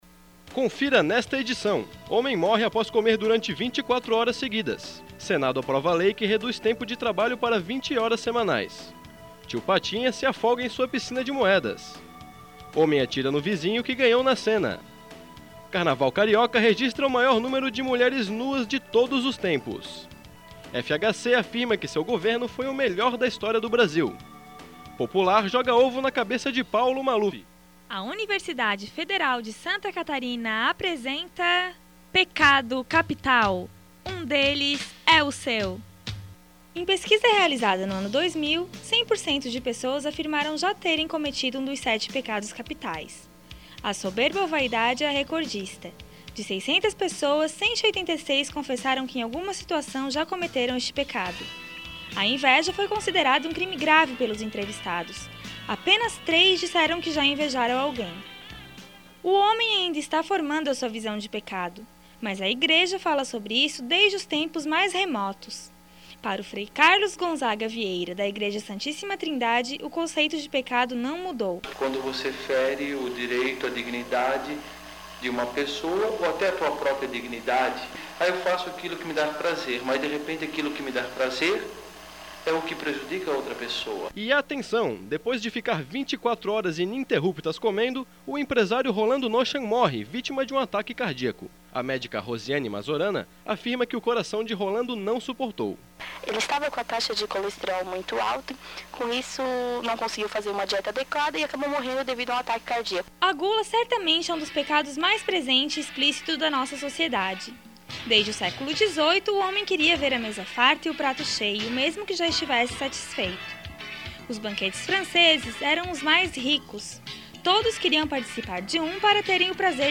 Documentário